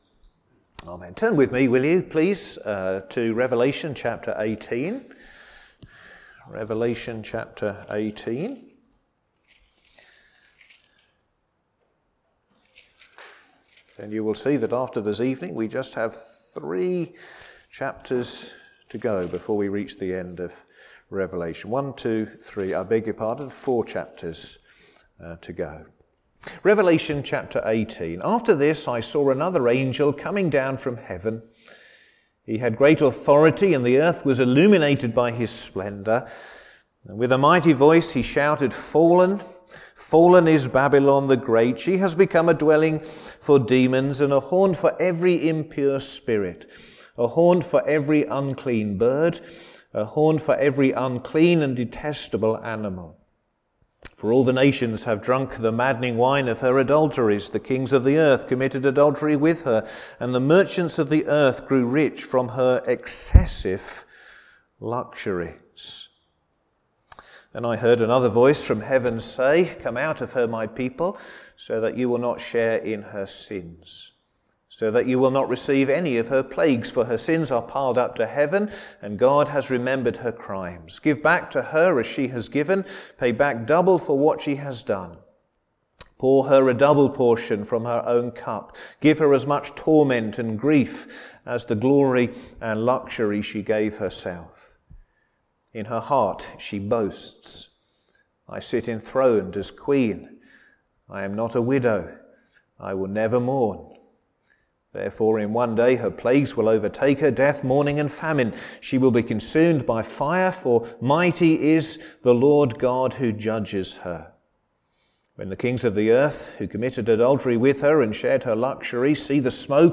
All sermons preached at Crockenhill Baptist Church